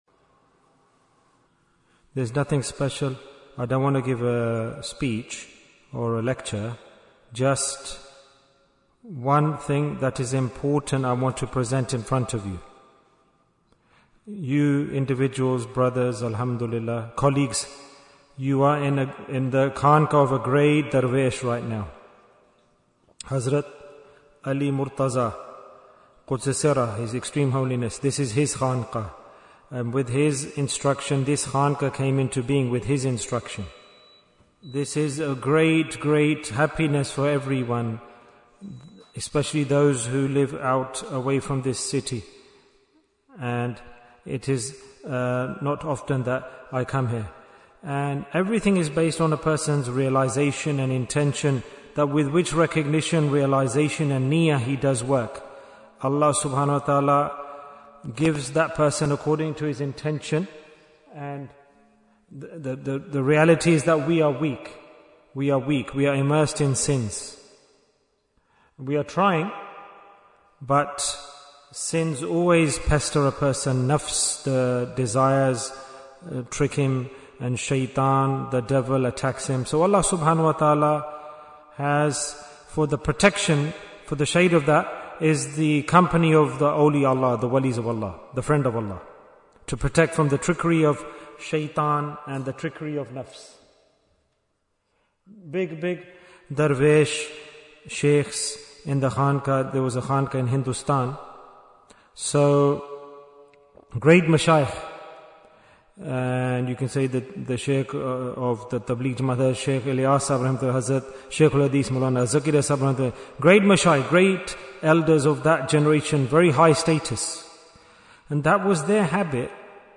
Bayan after Isha in Manchester Bayan, 15 minutes24th August, 2025